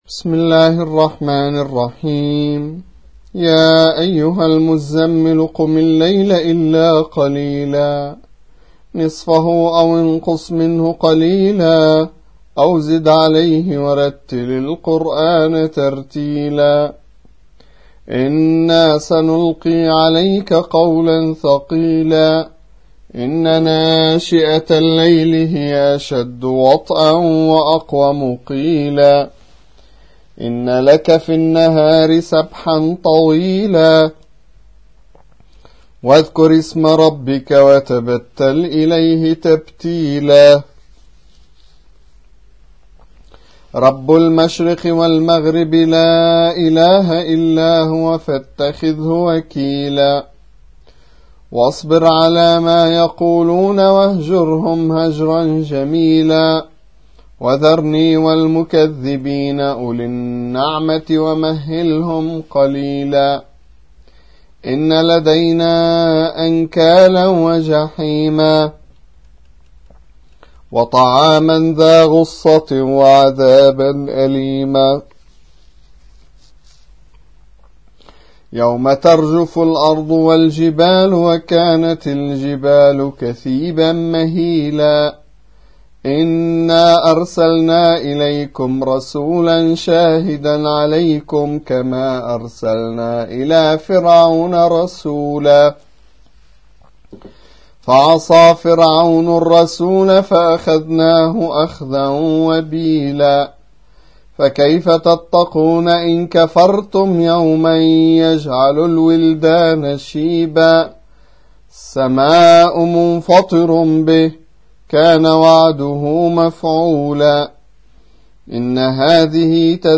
73. سورة المزمل / القارئ